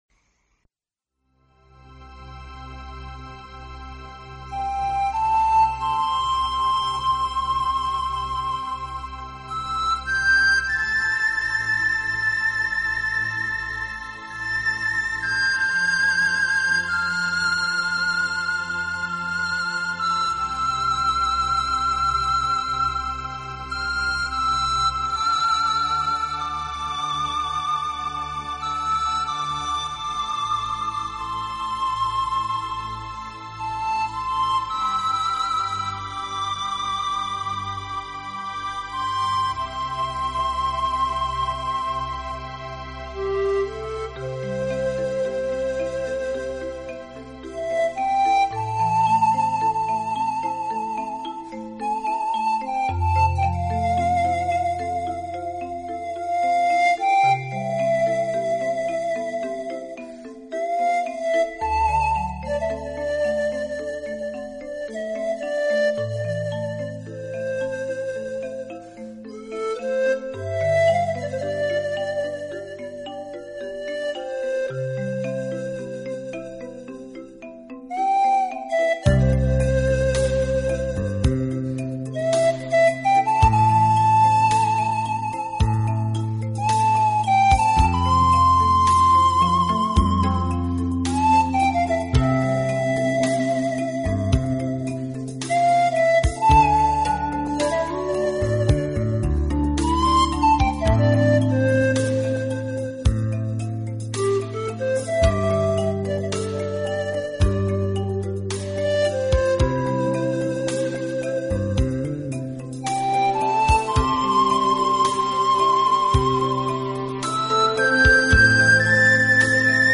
同时其录音又极其细致、干净、层次分明，配器简洁明